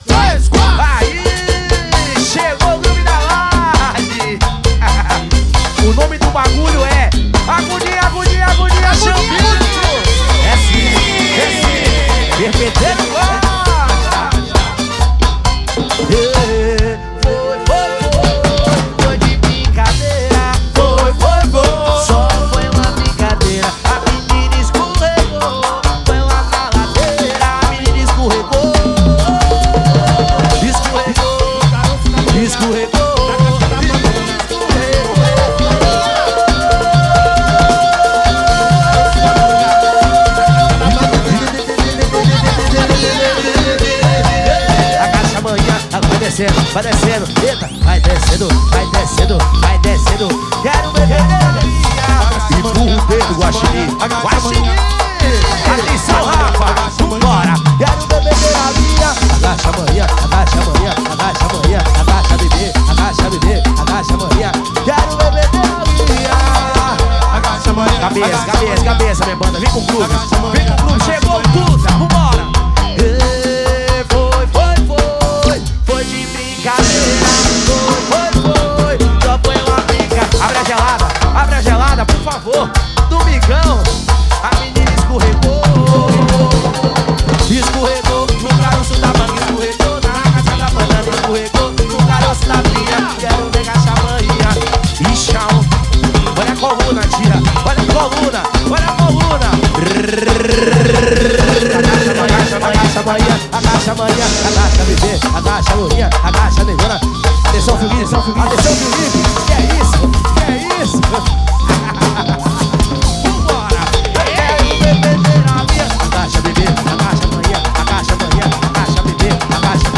agonia samba de roda samba duro verao 2022